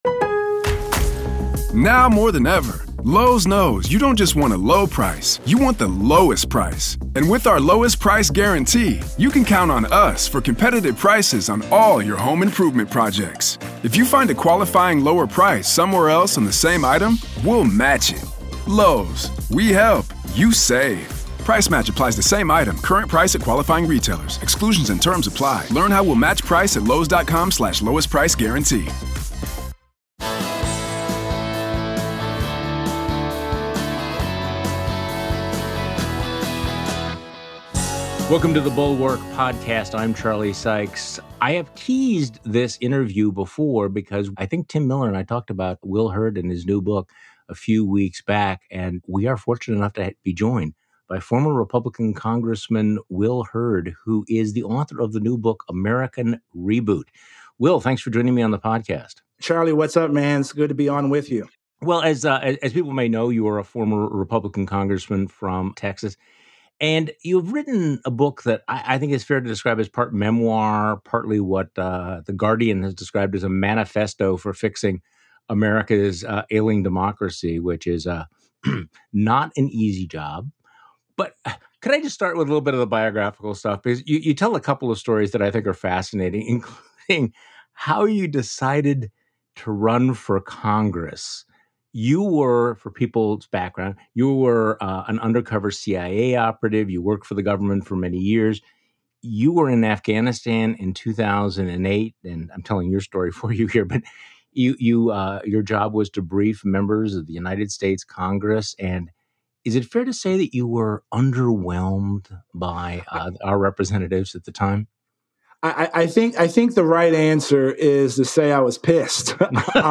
Will Hurd thinks there are enough normal Republicans out there to back a presidential candidate who works in the middle and across party lines. The former congressman joins Charlie Sykes on today's podcast.